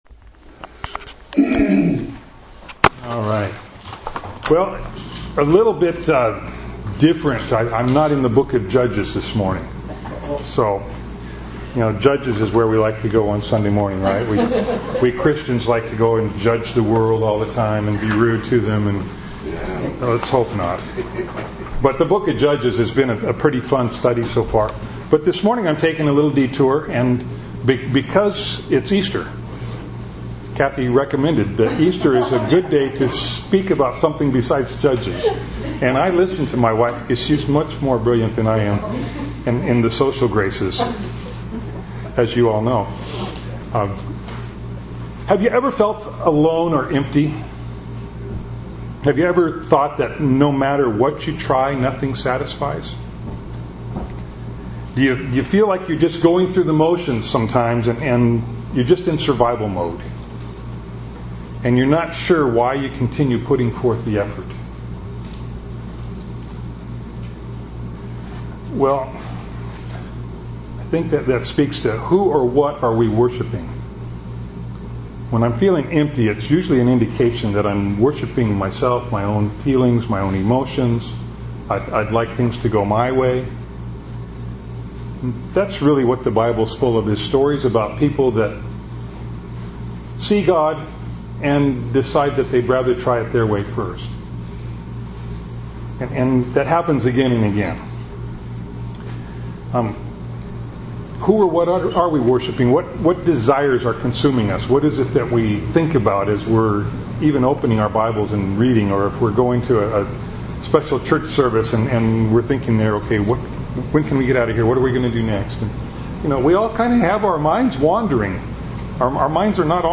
He Is Risen (Early Easter Service 2012)